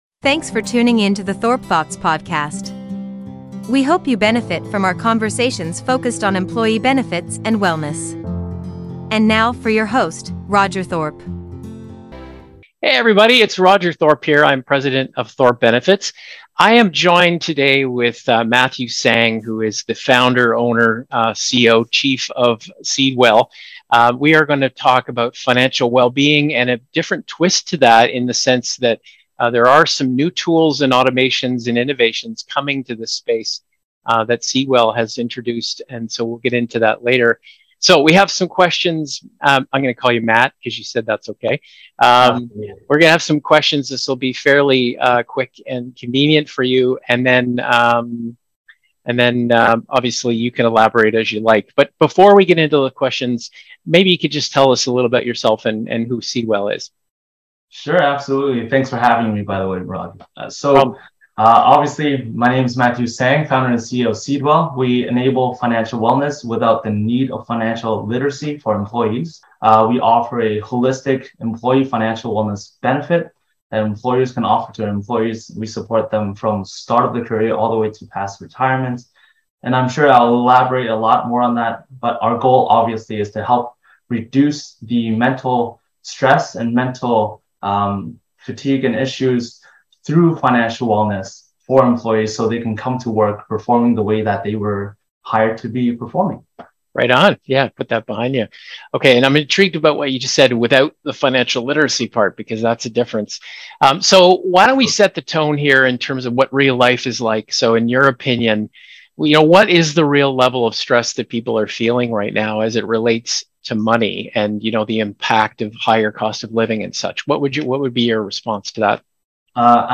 Seedwell Interview: Financial Well-Being and AI